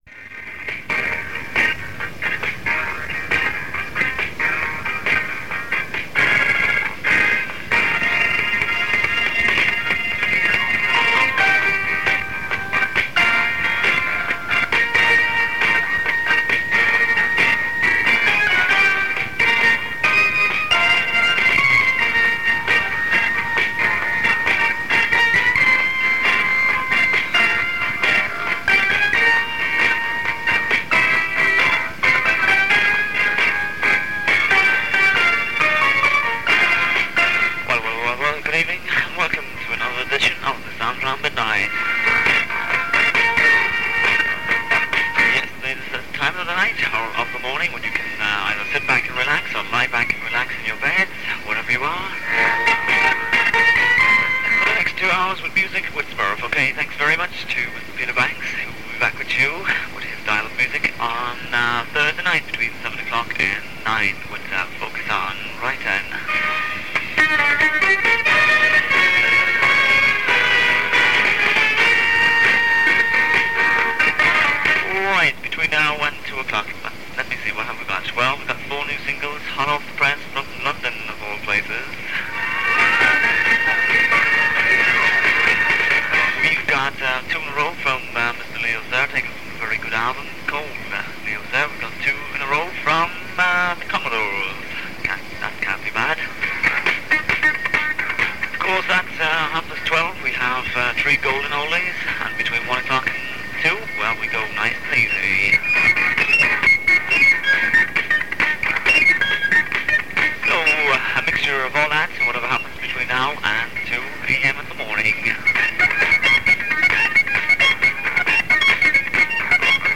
Adverts are heard for businesses around Cork as Radio City was beginning to generate income in its second month on air.
The recording was made from 1512 kHz, announcing 199 metres, between 20th and 27th October 1980 although exact dates are unclear. Audio quality is poor for the first half-hour due to rudimentary recording equipment but improves later despite cassette degradation.